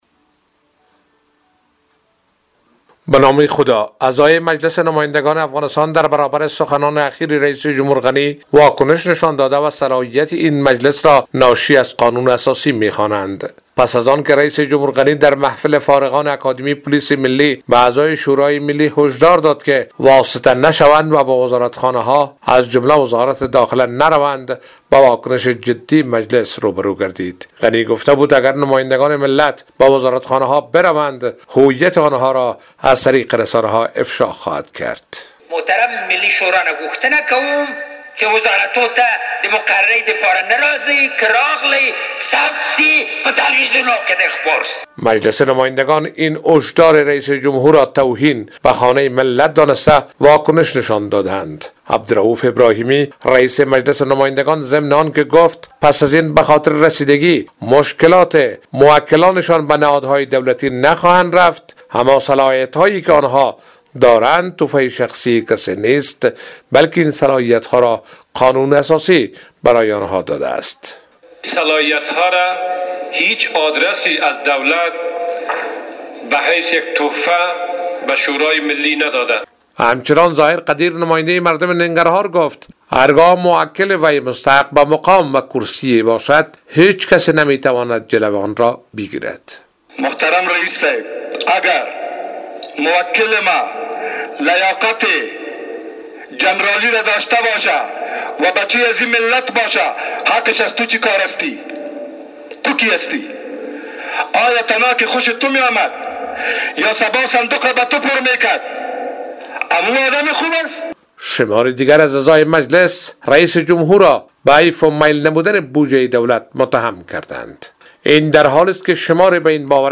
گزارش : واکنش مجلس نمایندگان افغانستان به سخنان اشرف غنی